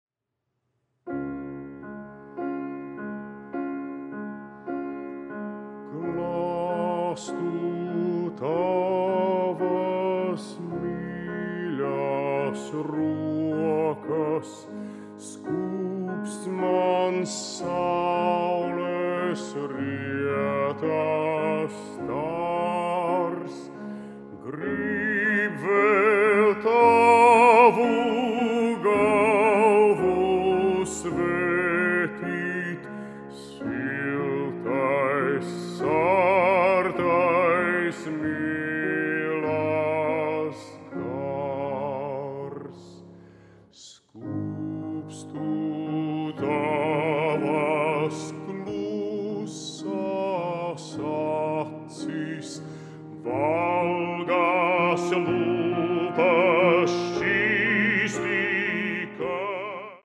Genre: Vocal chamber music
Instrumentation: piano, voice